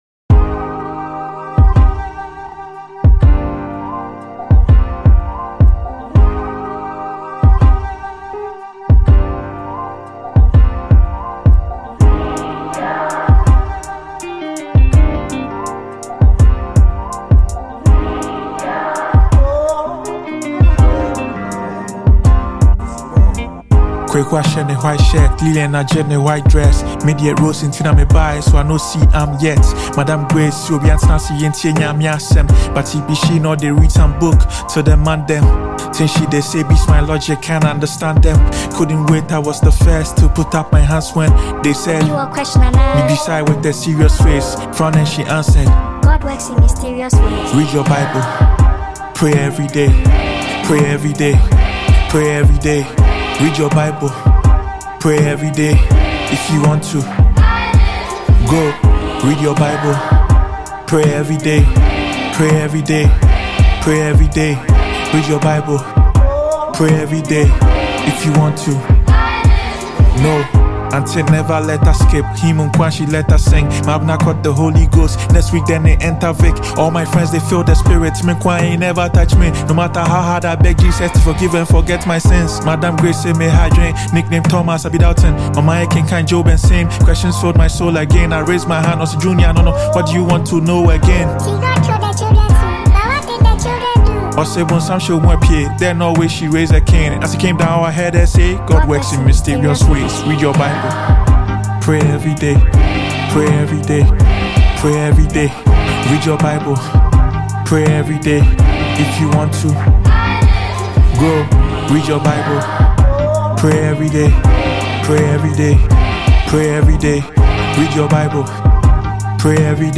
soulful and introspective song
Ghanaian rapper